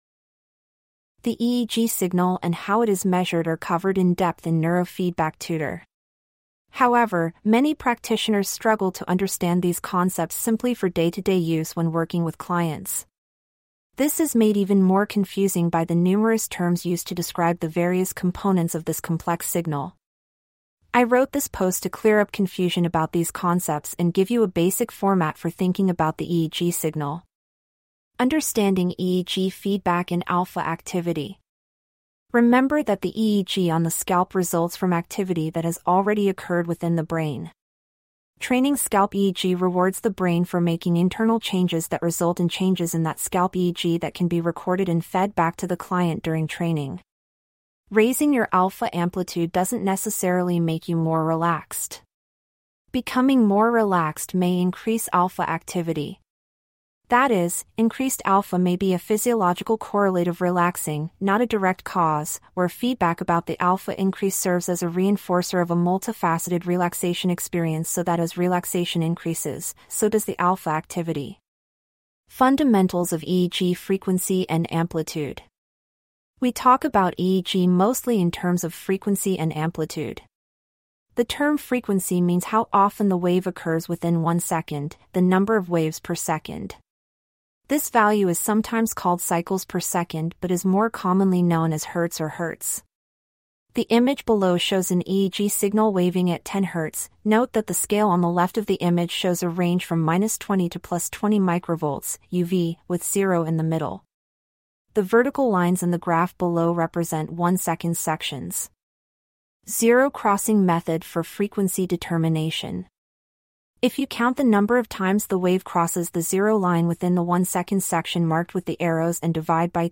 CLICK TO HEAR THIS POST NARRATED Understanding EEG Feedback and Alpha Activity Remember that the EEG on the scalp results from activity that has already occurred within the brain.